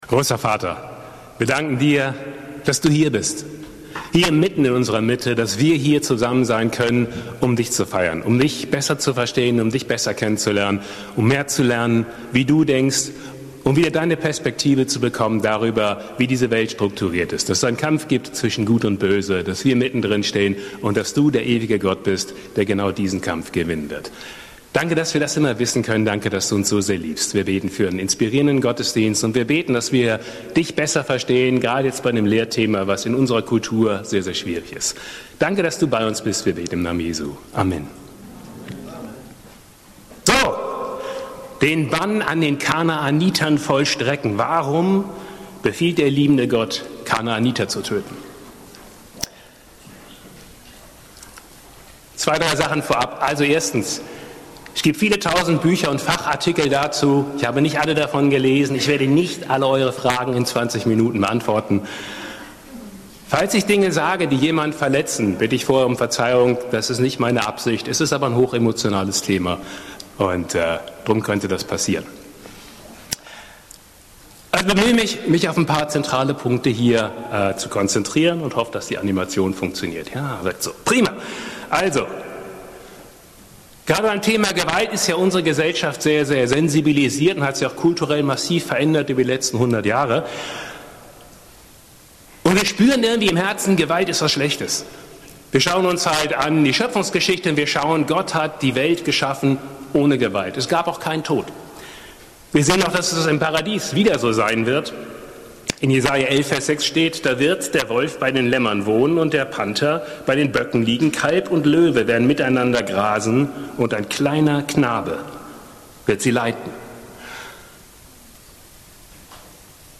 - Lehre ~ BGC Predigten Gottesdienst Podcast